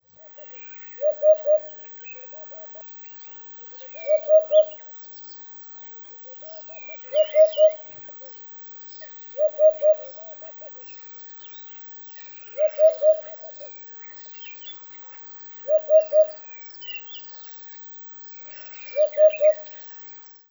Wiedehopf Ruf
Wiedehopf-Ruf-Voegel-in-Europa.wav